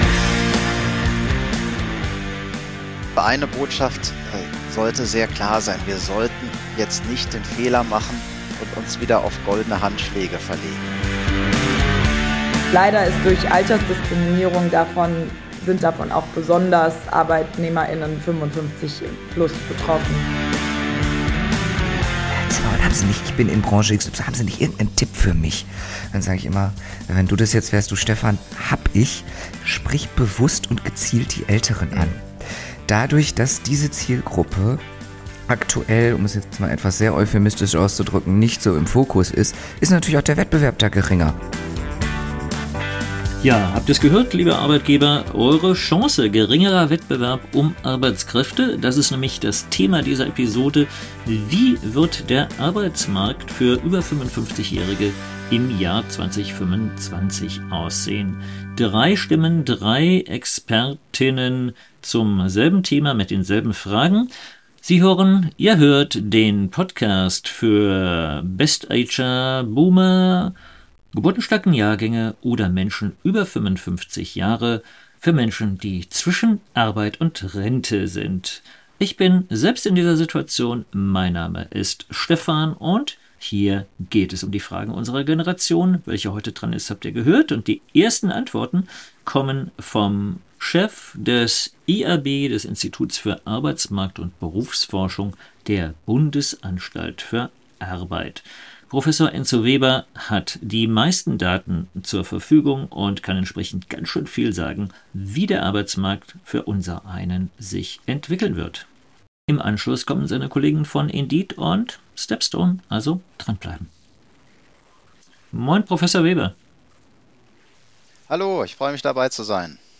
Der Beziehungsstatus Arbeitnehmer:in und Arbeitsmarkt ist kompliziert. Drei Arbeitsmarktexpert:innen von der Bundesanstalt für Arbeit, Indeed und Stepstone schauen voraus auf die Entwicklugen des Arbeitsmarktes speziell für die über 55Jährigen.